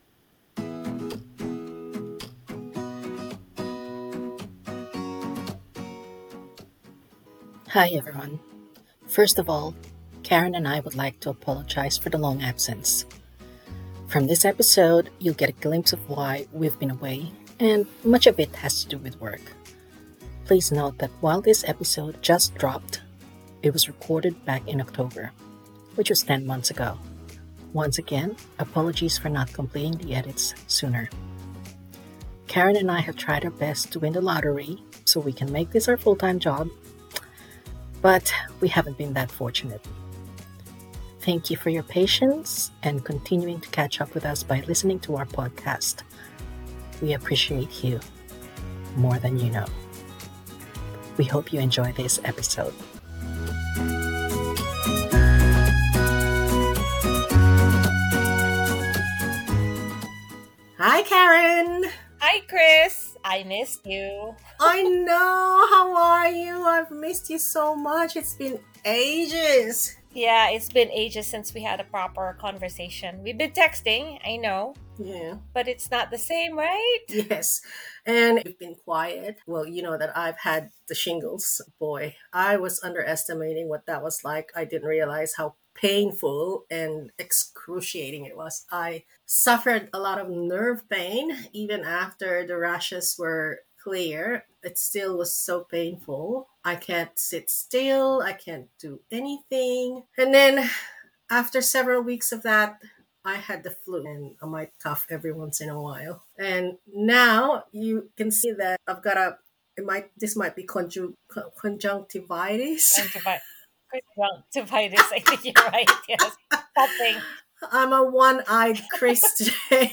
Two long-time friends, sharing their mental health struggles, supporting each other, and wanting their conversations to shed light on the impact of poor mental health.